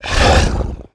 role5_wound1.wav